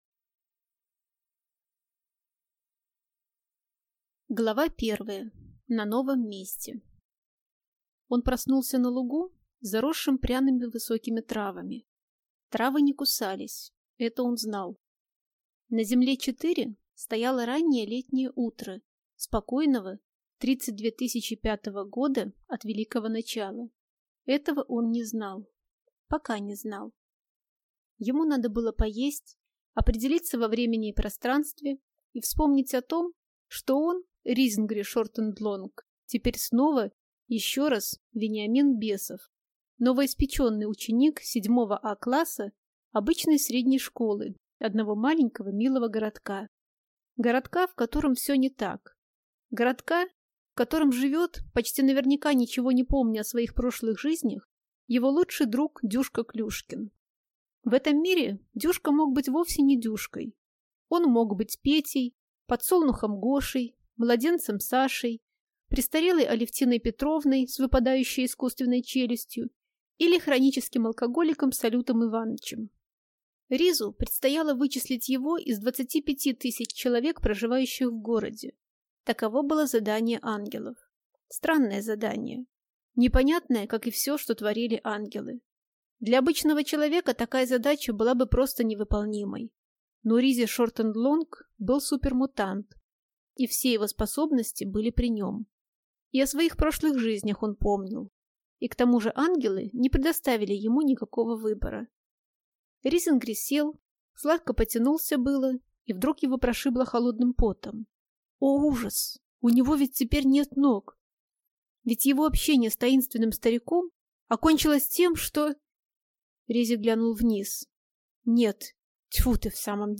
Аудиокнига Уровень Фи | Библиотека аудиокниг